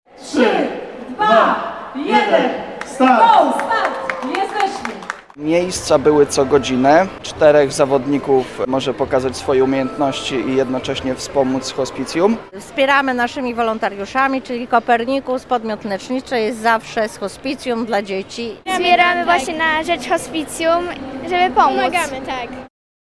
Posłuchaj głosu wolontariuszy pomagających na Sztafecie Nadziei: